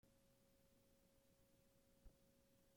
In most applications you can use a special method to create new tracks: just add (around) 3 sec. of silcence in the playlist between each track in the playlist.
silence.mp3